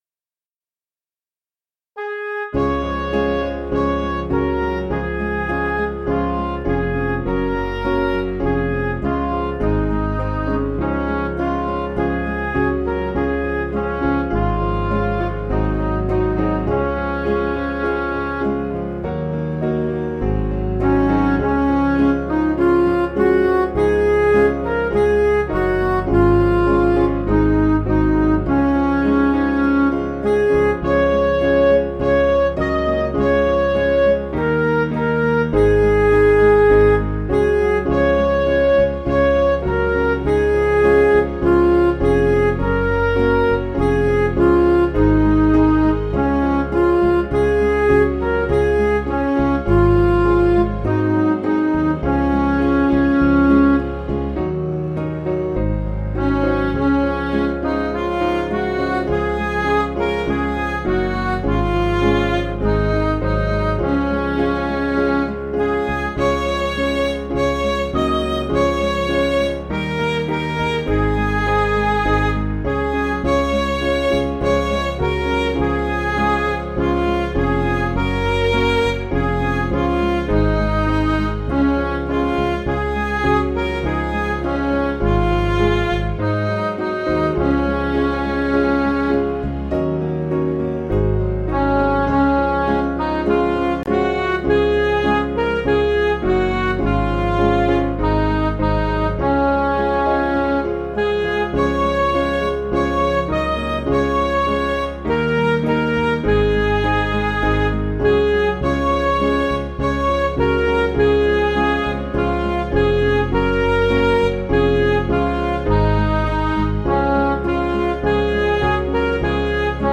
Piano & Instrumental
(CM)   5/Db